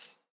Download Sfx Getcoin sound effect for video, games and apps.
Sfx Getcoin Sound Effect
sfx-getcoin-1.mp3